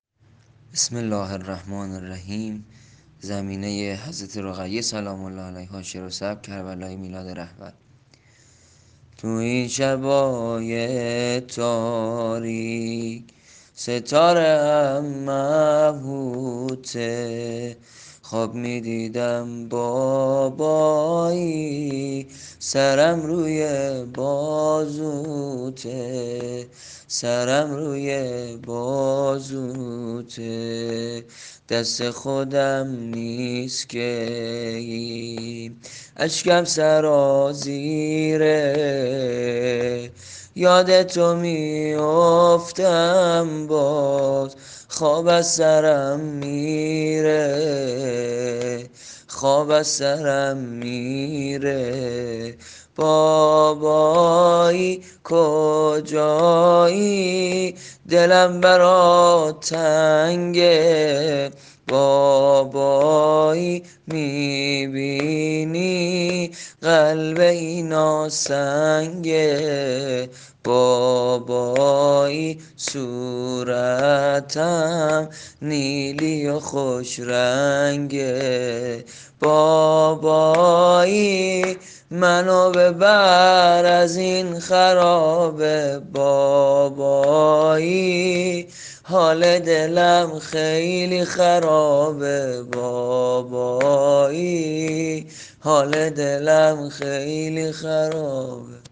زمینه حضرت رقیه سلام الله علیها